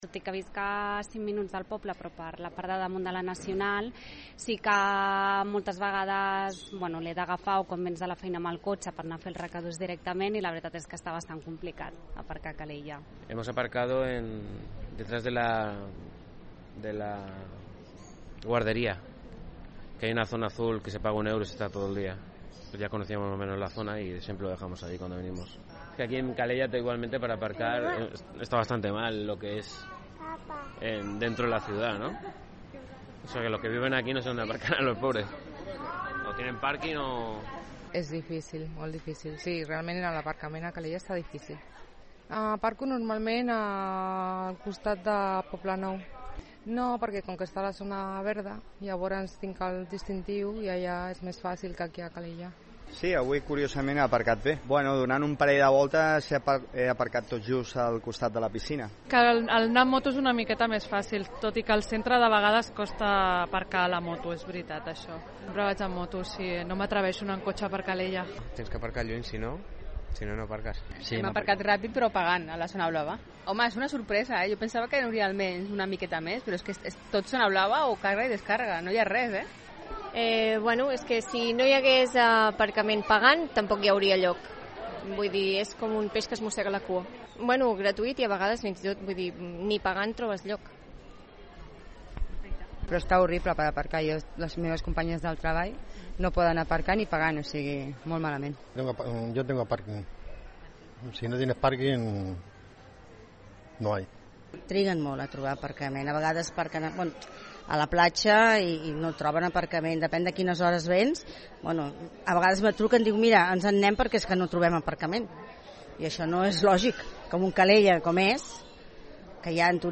Hem anat a preguntar als veïns de Calella que pensen sobre l’aparcament perquè és una qüestió polèmica a les xarxes socials.
A continuació podeu escoltar les opinions de la gent
ENQUESTA-APARCAMENT.mp3